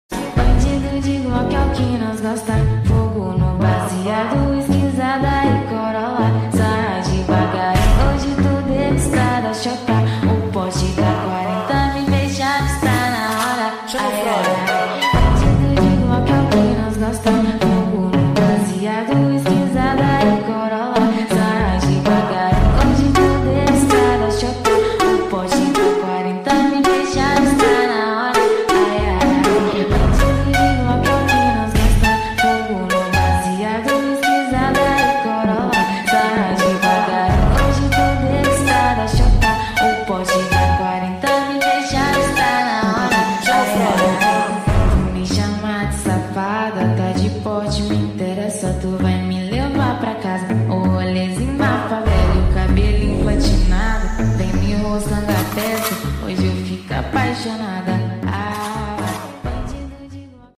8D